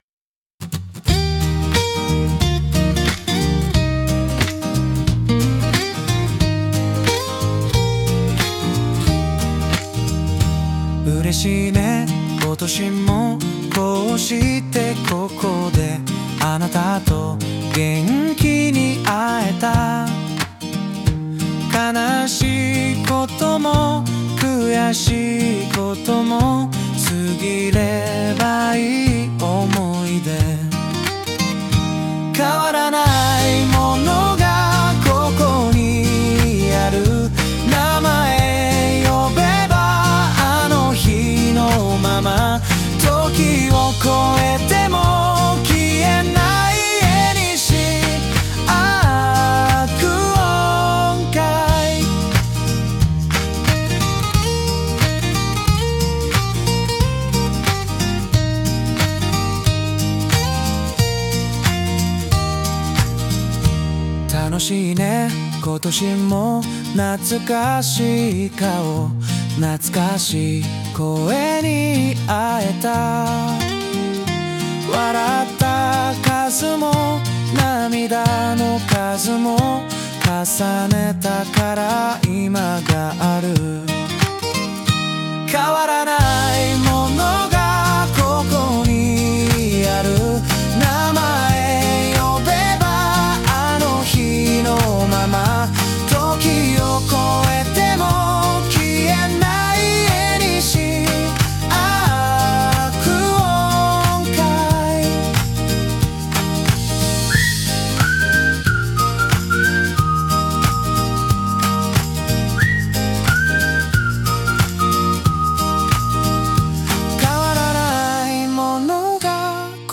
ボーカルやサウンド面には最新のAI技術を活用して制作されています。 プロの表現力と新しい技術が融合した、非常に現代的で瑞々しい作品となっています。